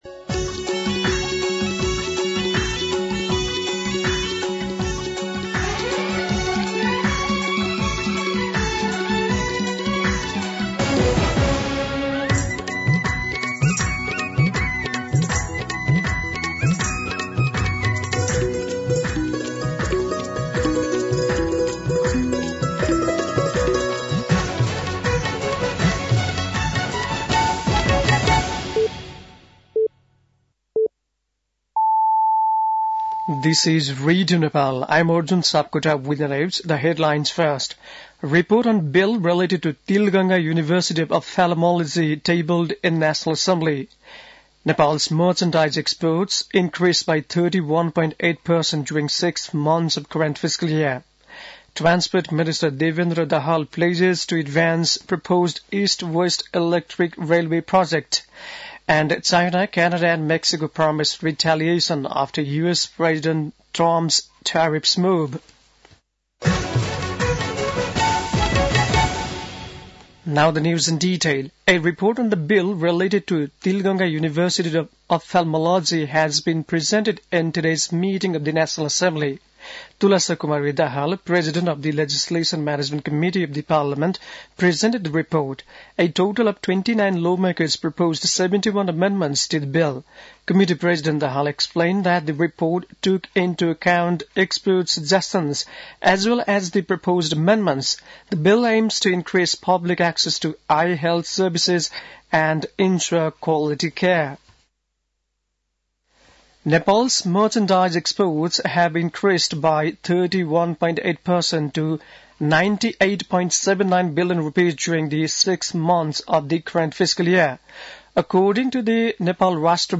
बेलुकी ८ बजेको अङ्ग्रेजी समाचार : २१ माघ , २०८१
8-PM-English-News-10-20.mp3